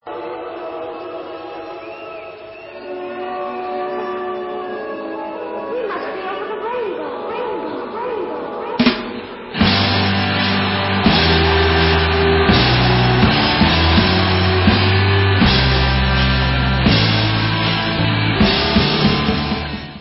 sledovat novinky v oddělení Hard Rock